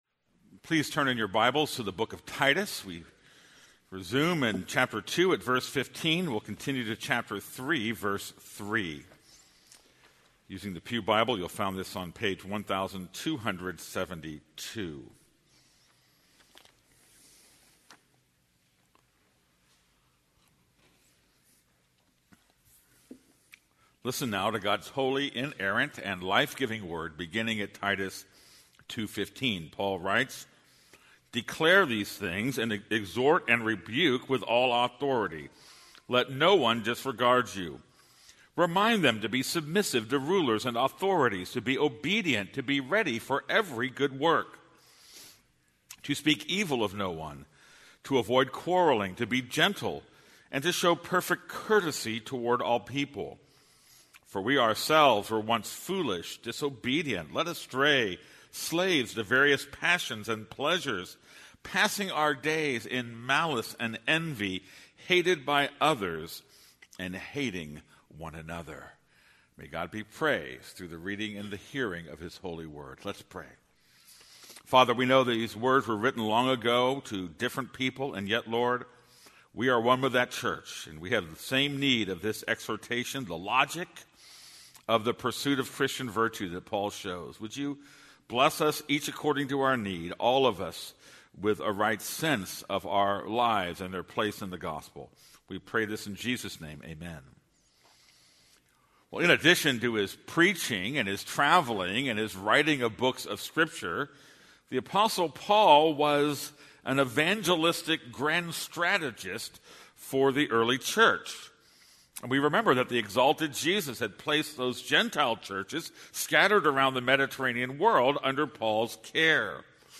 This is a sermon on Titus 2:15.